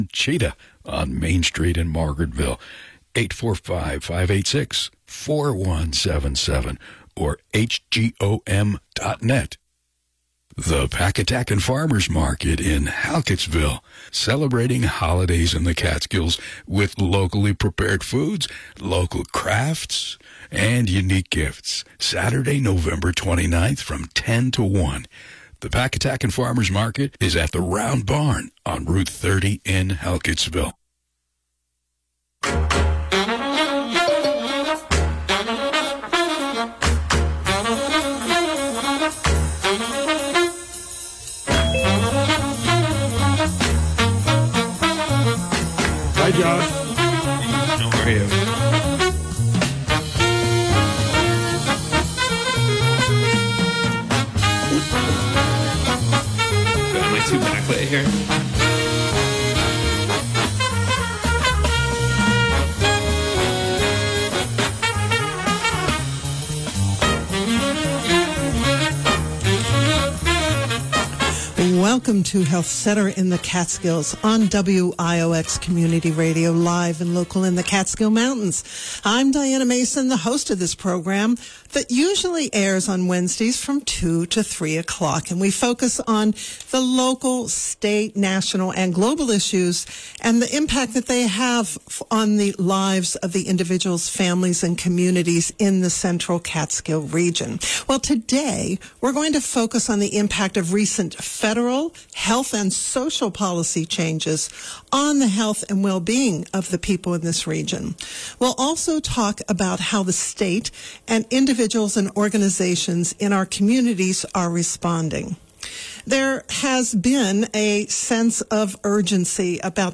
The special opens with Congressman Josh Riley (D, NY-19) discussing the federal changes that have occurred in the past year in the One Big Beautiful Bill Act and the issues related to the 2025 government shutdown; followed by a focus on the impact of these changes on hospital systems in the region.